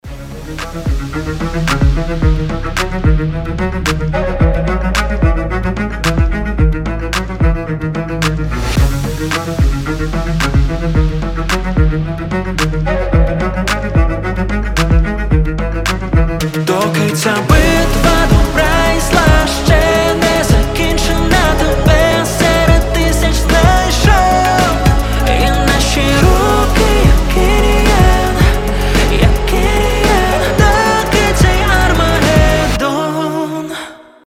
красивый мужской голос
нарастающие
виолончель